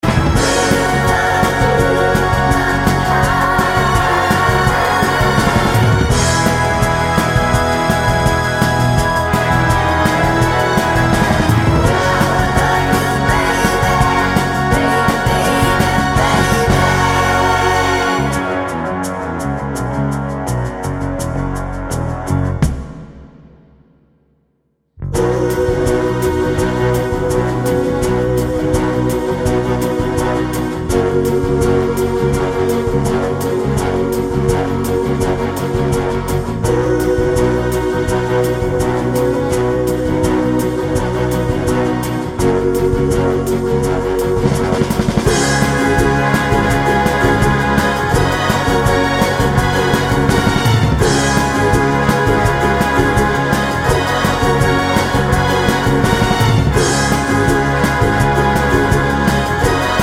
4 Semitones Down Soul / Motown 3:35 Buy £1.50